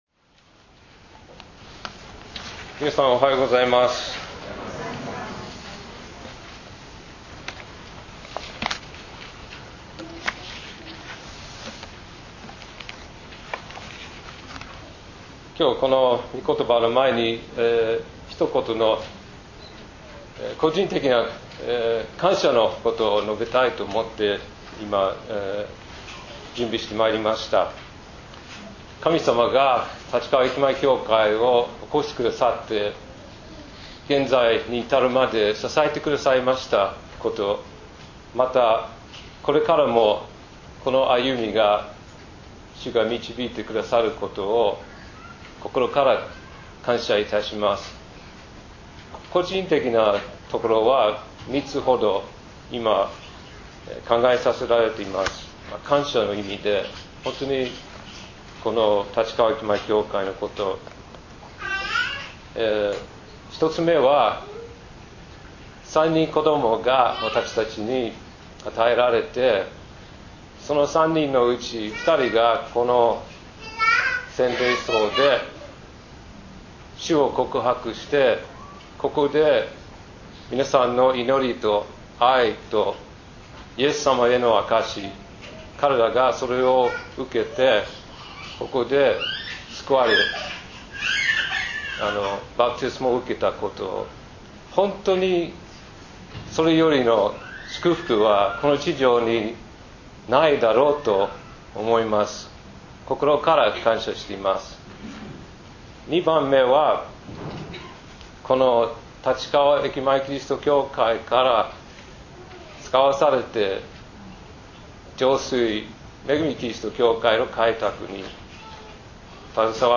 礼拝宣教録音 – 聖霊に満たされなさい